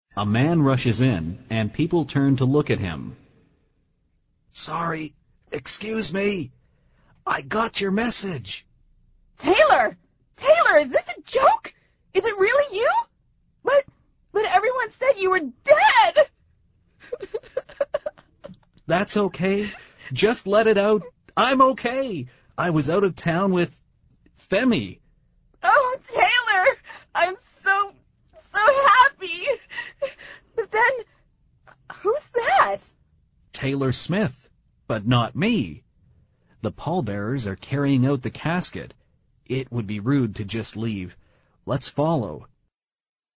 美语会话实录第252期(MP3+文本):Let it out!